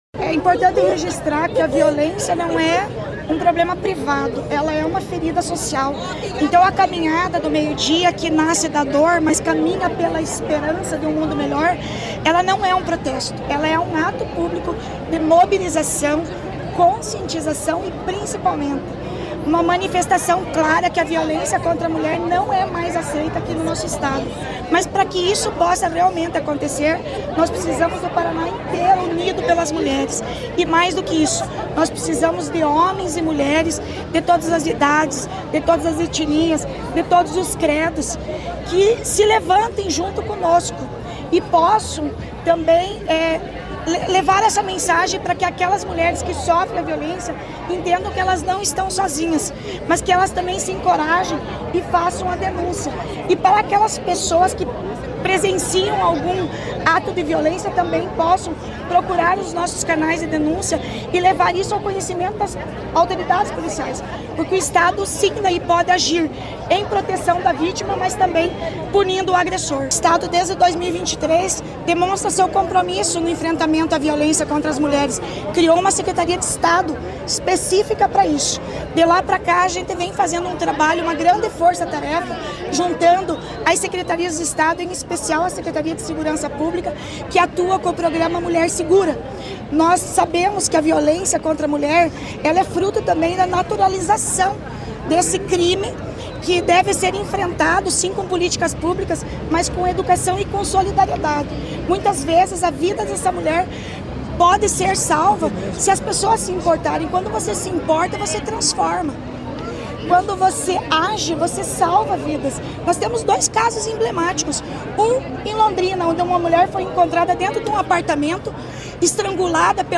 Sonora da secretária da Mulher, Igualdade Racial e Pessoa Idosa, Leandre Dal Ponte, sobre a Caminhada do Meio-Dia no Dia Estadual de Combate ao Feminicídio